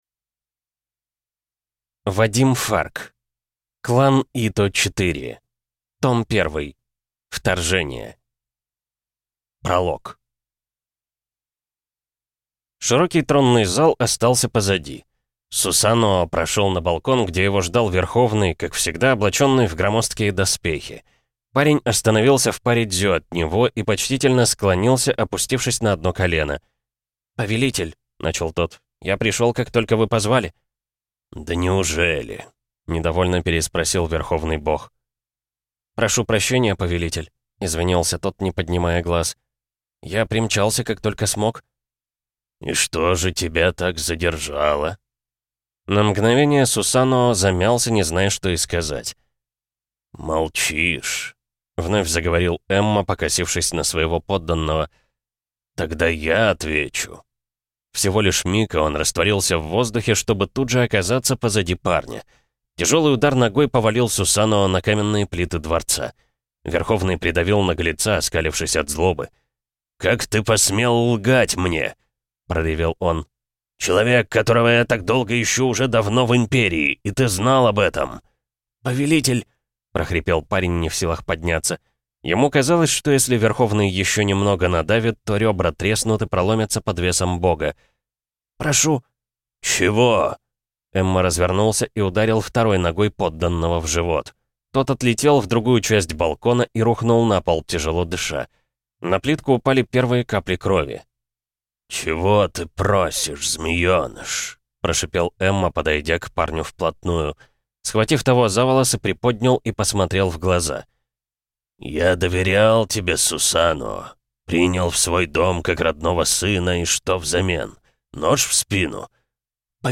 Аудиокнига Клан Ито. Вторжение. Том 1 | Библиотека аудиокниг